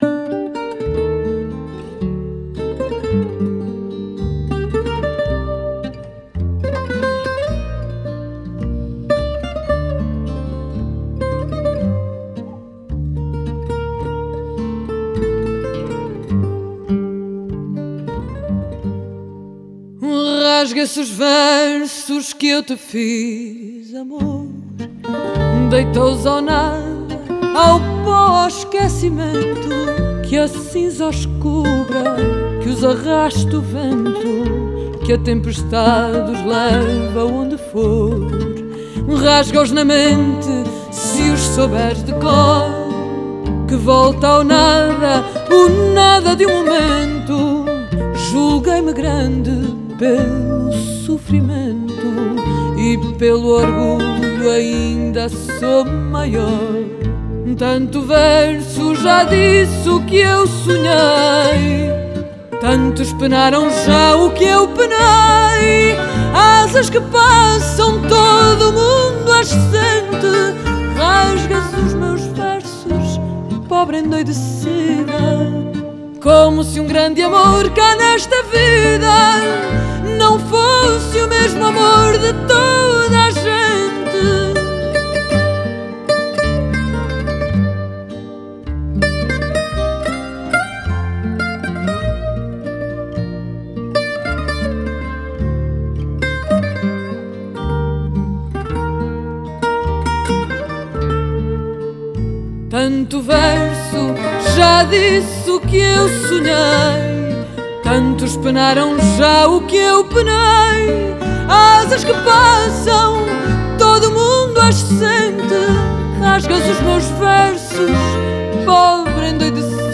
Genre: Folk, World, & Country
Style: Fado